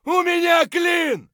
gun_jam_5.ogg